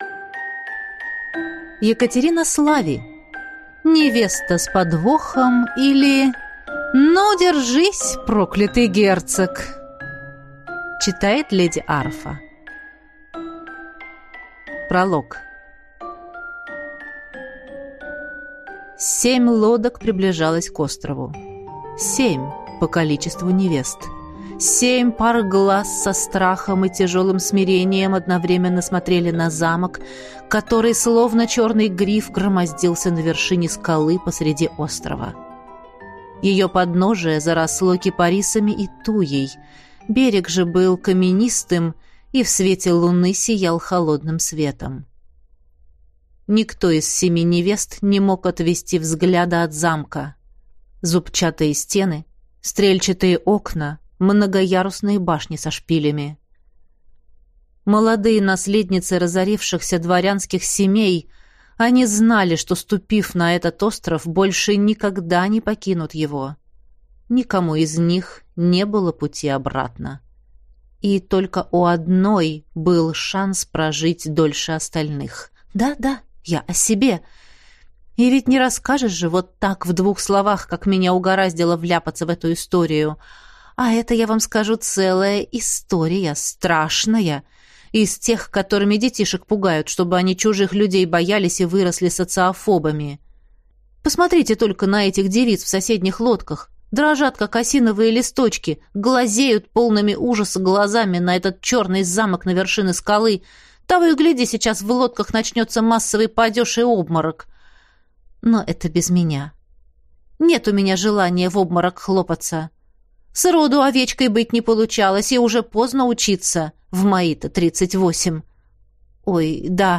Аудиокнига Невеста с подвохом, или Ну, держись, Проклятый Герцог!